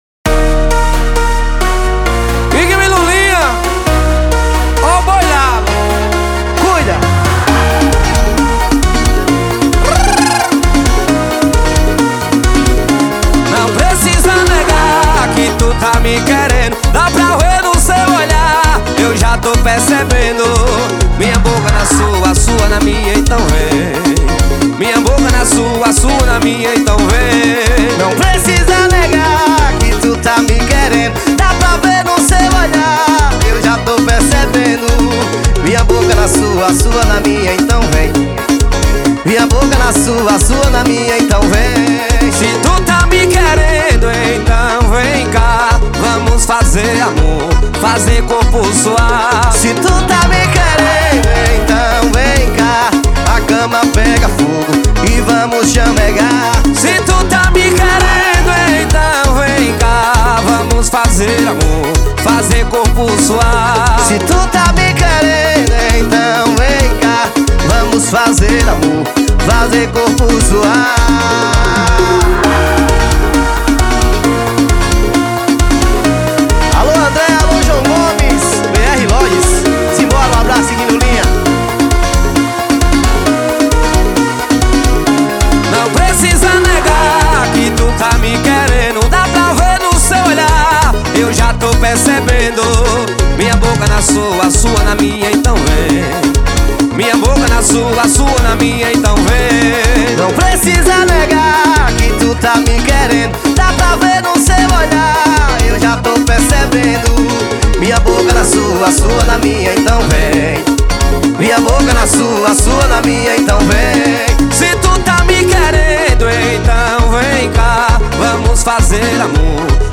2024-02-14 18:15:46 Gênero: Forró Views